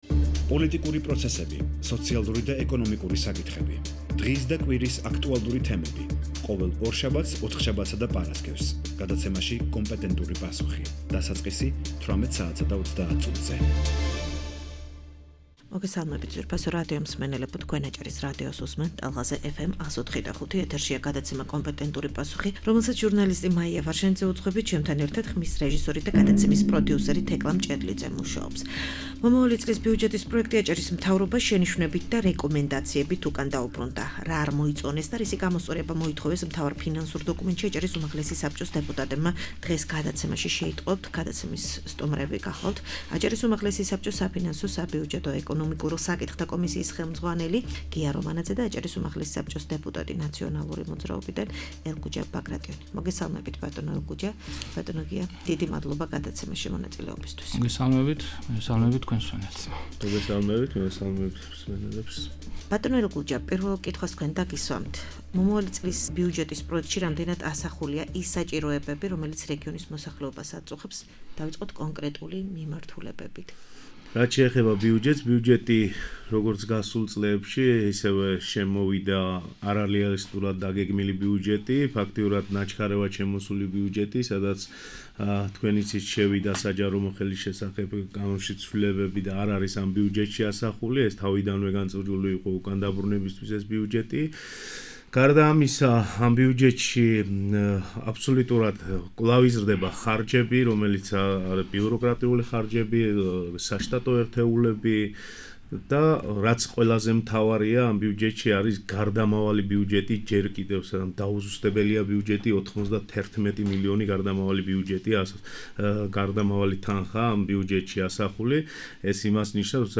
მომავალი წლის ბიუჯეტის პროექტი აჭარის მთავრობას შენიშვნებით და რეკომენდაციებით უკან დაუბრუნდა. რა არ მოიწონეს და რისი გამოსწორება მოითხოვეს მთავარ ფინანსურ დოკუმენტში აჭარის უმაღლესი საბჭოს დეპუტატებმა. დღეს fm 104.5-ზე „კომპეტენტურ პასუხში“ გადაცემაში სტუმრად იყვნენ აჭარის უმაღლესი საბჭოს საფინანსო-საბიუჯეტო და ეკონომიკურ საკითხთა კომისიის თავმჯდომარე გია რომანაძე და აჭარის უმაღლესი საბჭოს დეპუტატი ნაციონალური მოძრაობიდან ელგუჯა ბაგრატიონი.